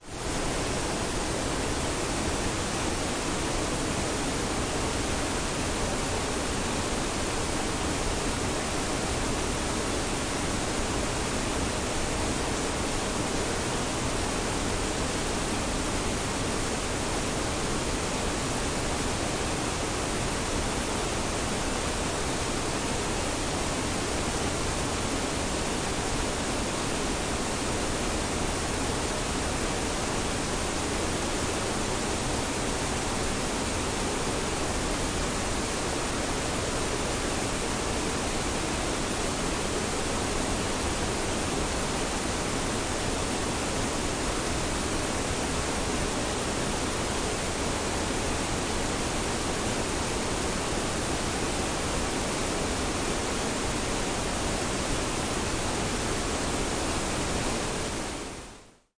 waterfall.mp3